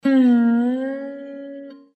The slide guitar solo comes from a classical Indian record that I sampled. It was playing with an accompanying tampura, I instructed the Noise removal tool in Adobe Audition that tampura was the unwanted noise, and I got the slide guitar alone!
Here are some of the de-tampurised samples: